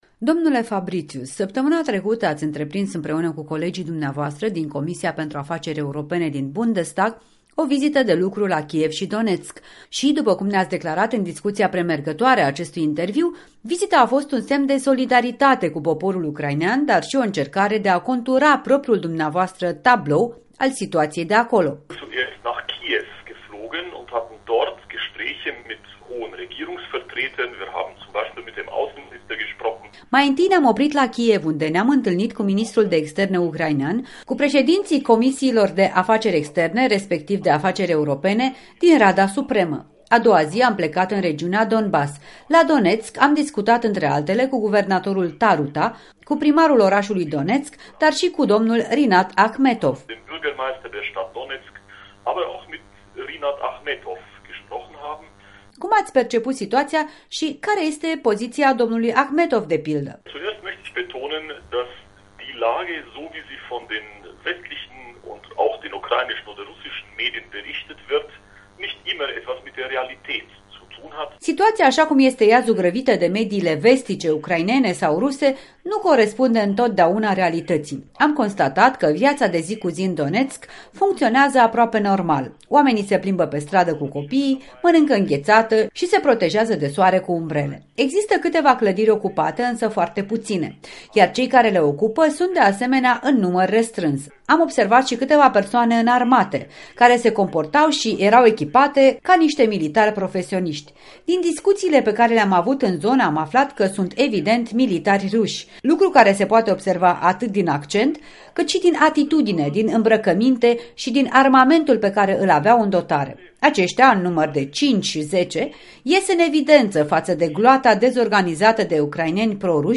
Interviu cu parlamentaru german Bernd Fabritius